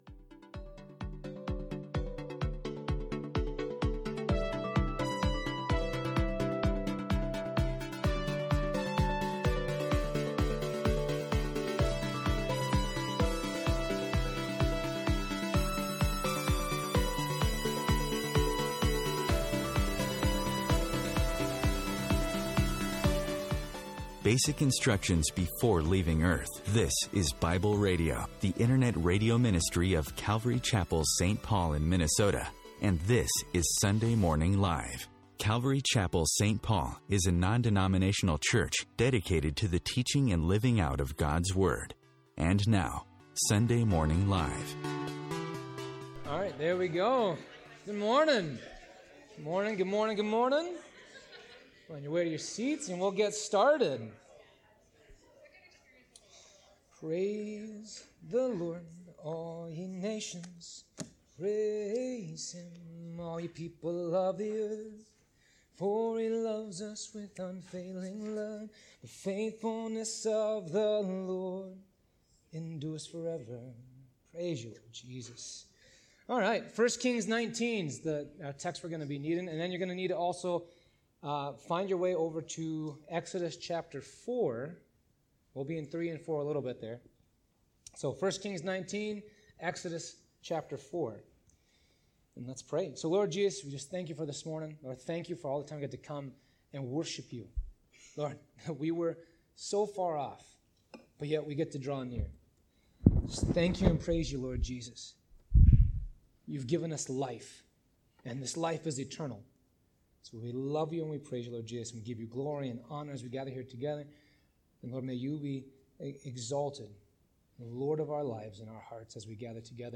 Related Topics/Books: 1 Kings, Guest Speakers | More Messages from Guest Speaker | Download Audio